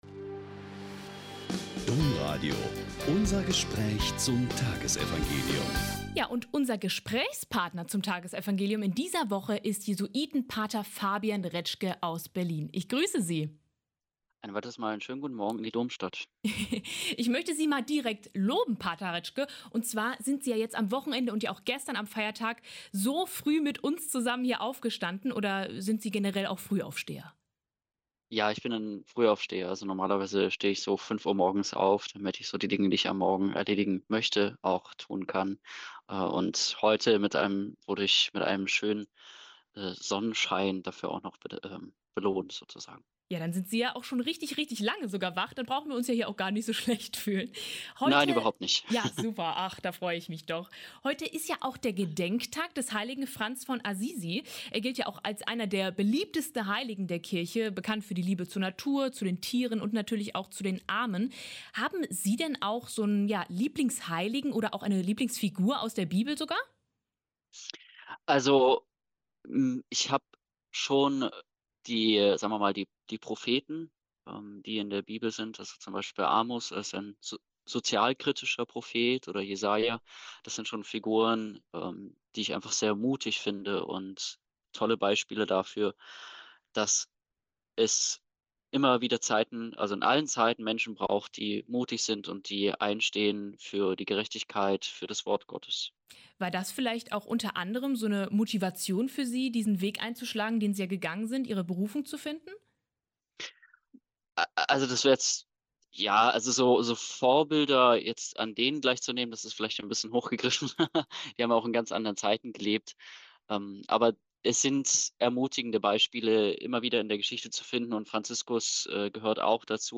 Lk 10,17-24 - Gespräch